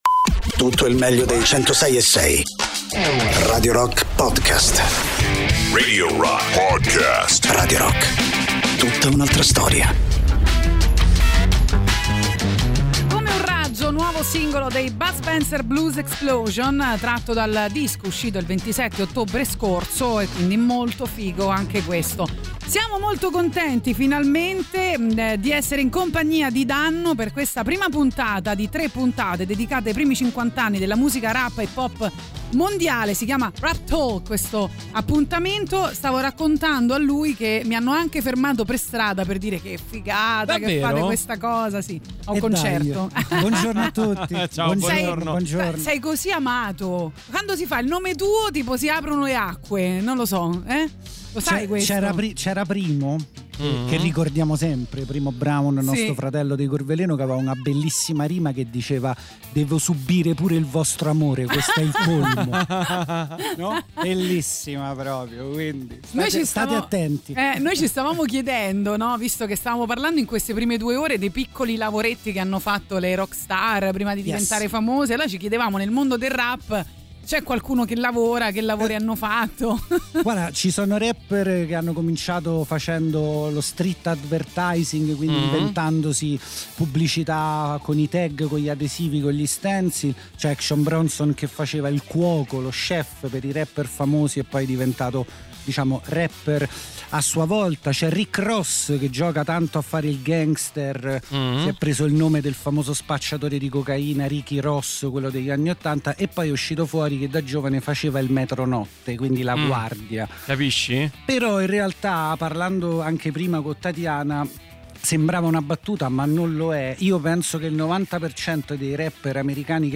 ospite negli studi di Radio Rock per Rap Talk, 3 puntate dedicate ai primi 50 anni della musica Rap e Hip-Hop Mondiale.